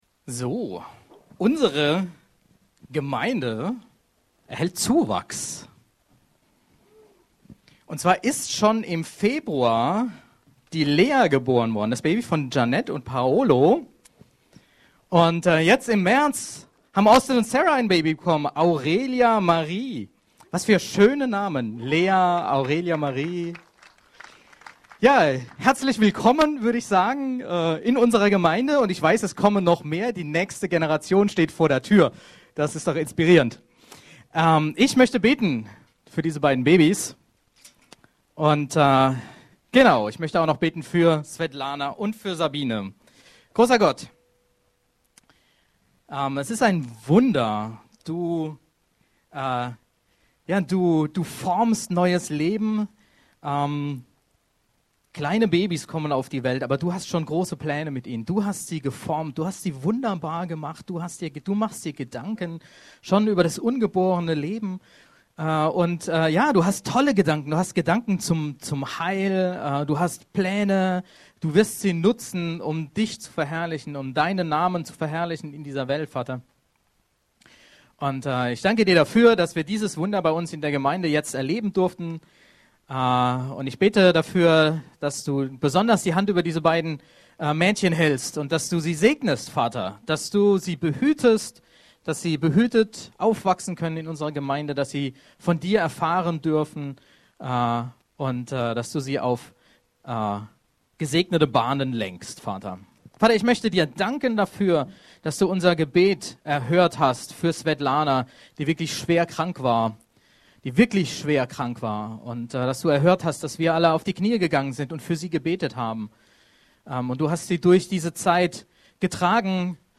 Predigten - Berliner Gemeinde Christi